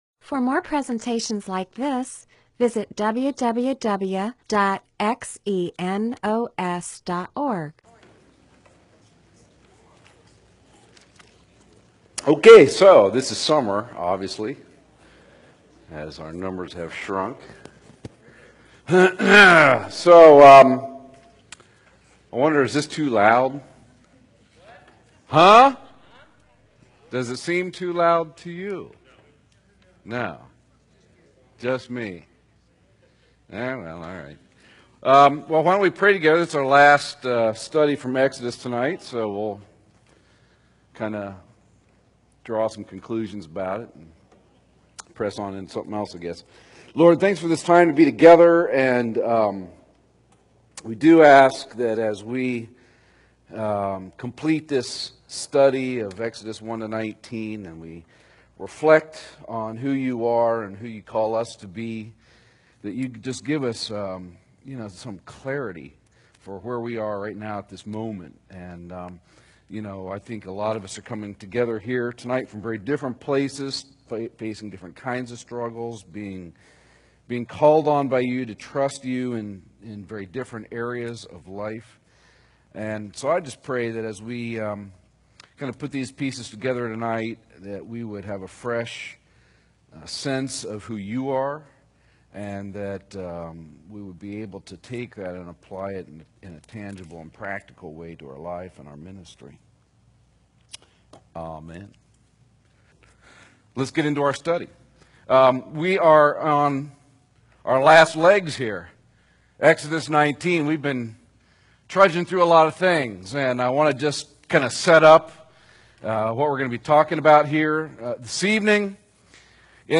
Bible teaching (presentation, sermon) on Exodus 19:1-6, Title: On Eagles' Wings, Date: 01/01/2008, Teacher